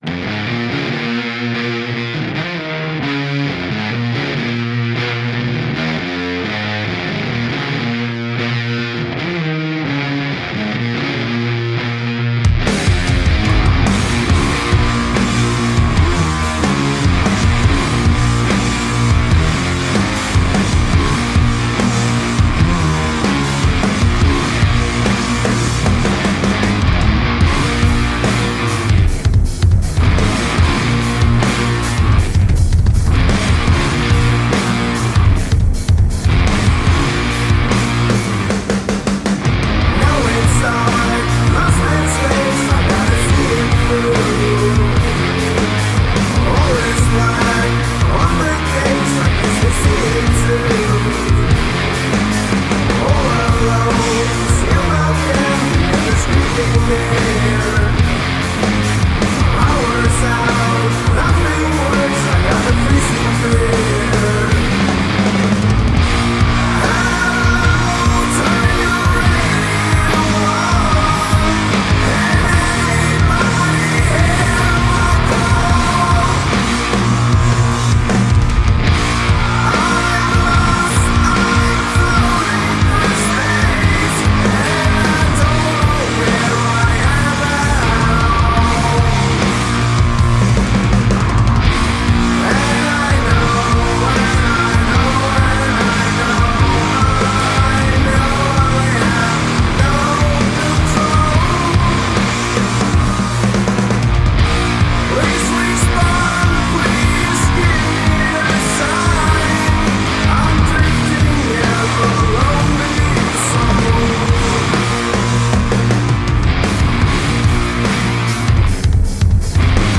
DOOM MUSIC